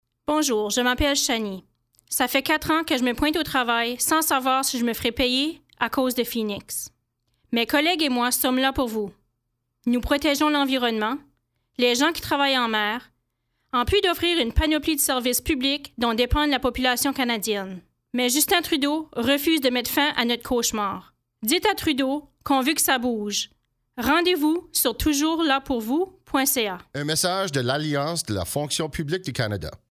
Annonce radio francophone N.-B.1.15 Mo